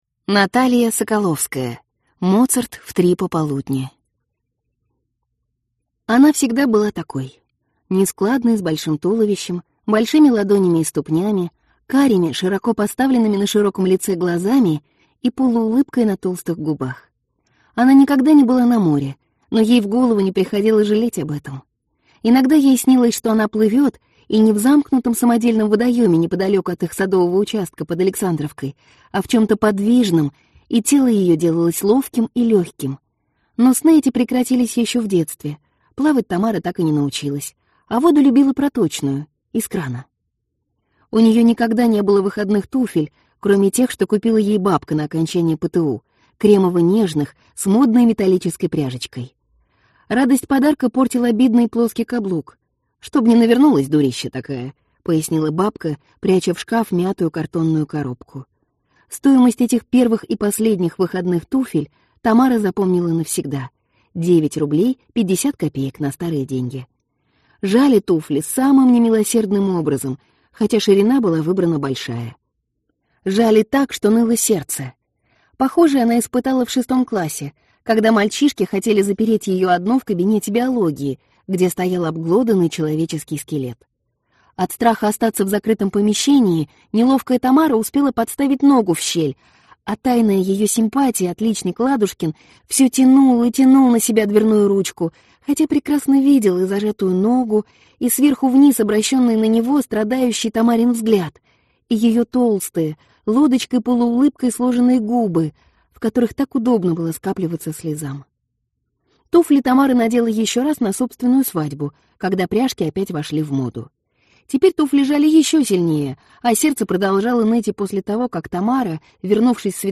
Аудиокнига Моцарт в три пополудни | Библиотека аудиокниг